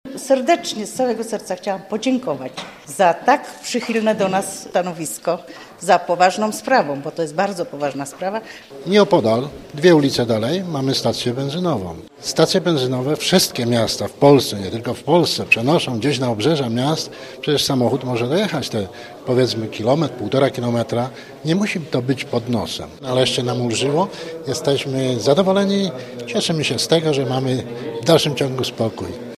Po czterech latach batalii nareszcie nam ulżyło – mówili mieszkańcy i dziękowali prezydentowi i radzie miasta za taką decyzję: